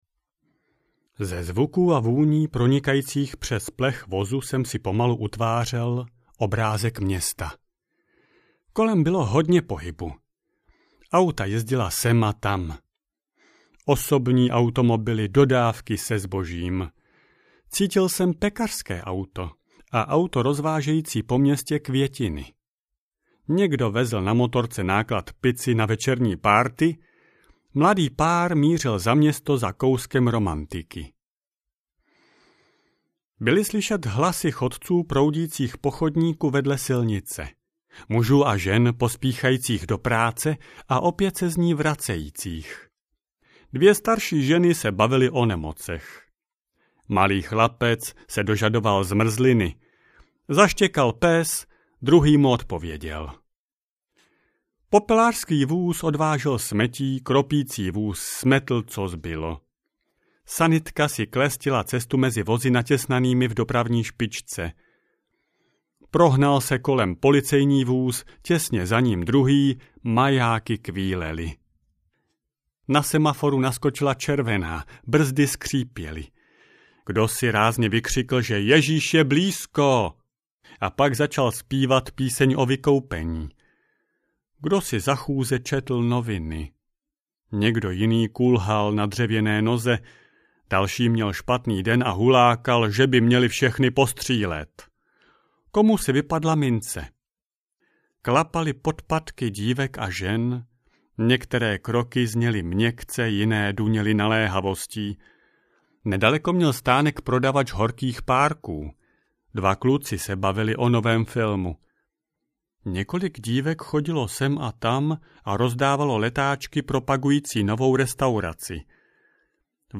Plyšový Buddha audiokniha
Ukázka z knihy
plysovy-buddha-audiokniha